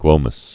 (glōməs)